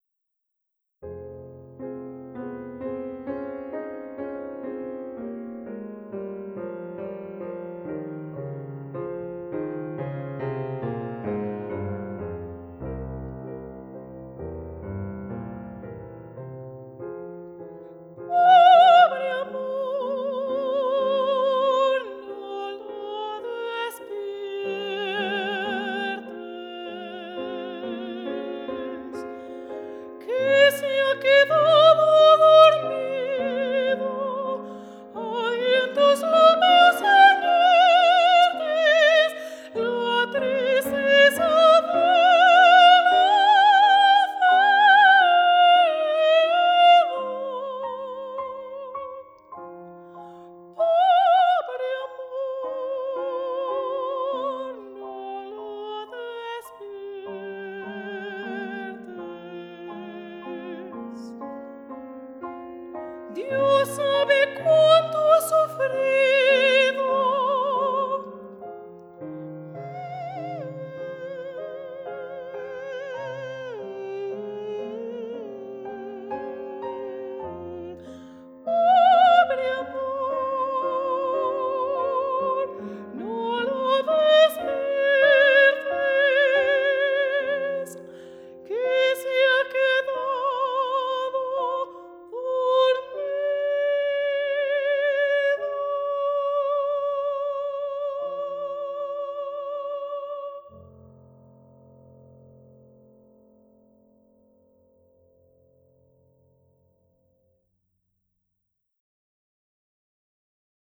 Soprano
Tenor